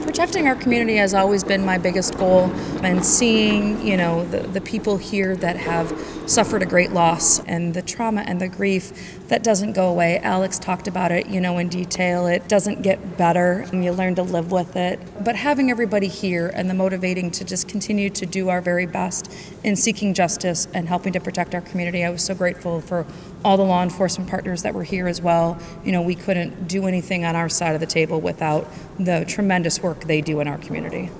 Prosecutor Guernsey spoke with WKHM about how her experience with the Vigil motivates her to use her position as Prosecutor to protect the community and seek justice for victims.